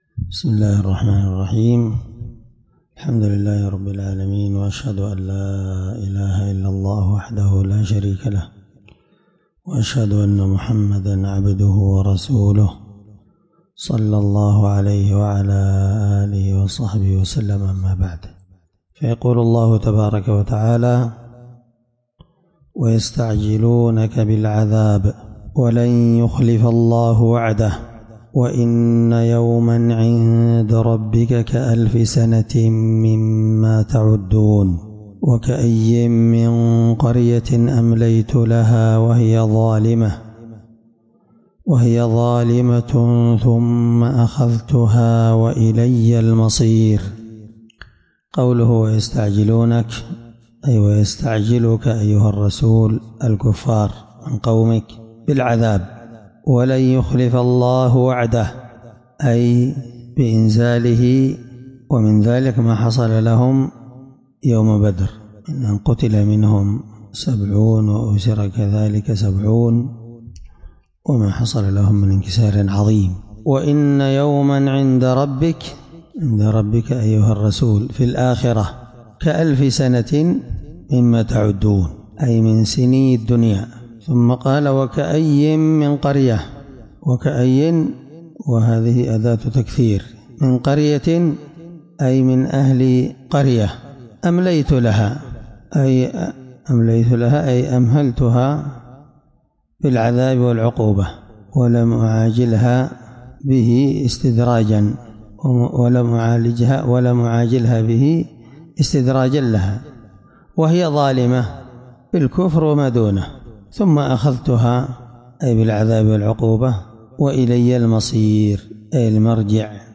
الدرس19تفسير آية (47-48) من سورة الحج